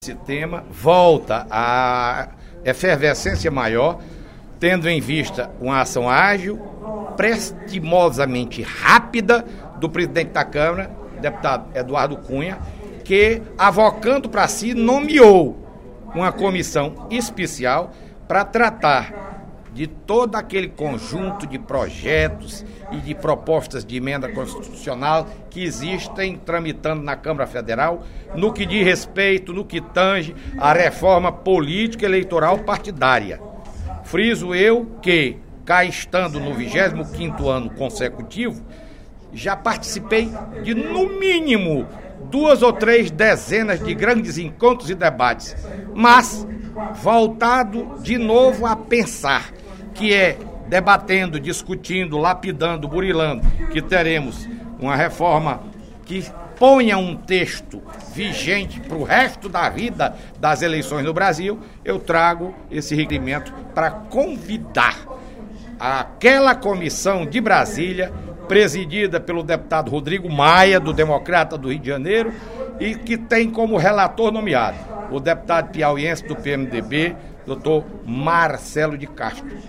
O deputado Fernando Hugo (SD) destacou, durante o primeiro expediente da sessão plenária desta sexta-feira (13/02), requerimento, de sua autoria, solicitando realização de sessão especial para debater a reforma política, com a participação de membros da Comissão Especial da Reforma Política da Câmara Federal.